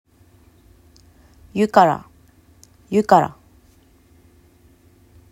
ユカㇻ               　　yúkar          英雄叙事詩